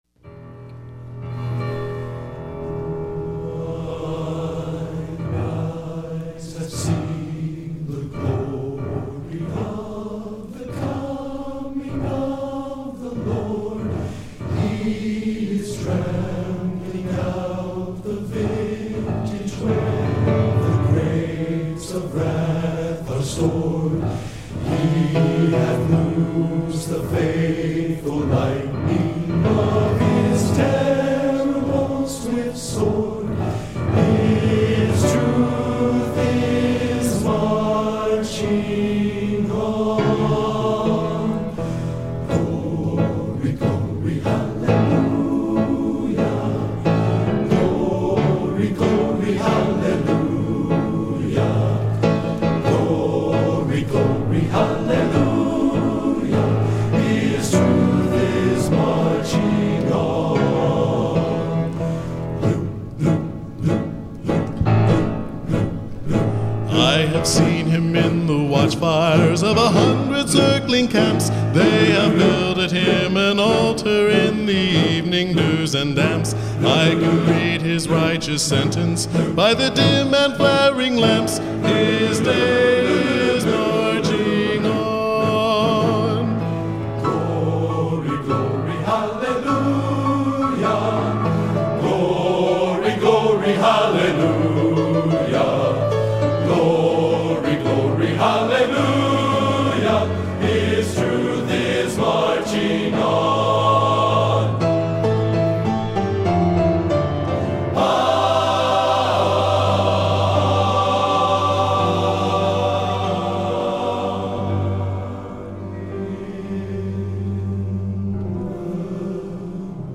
Genre: Patriotic | Type:
Anyone know the soloist on this track?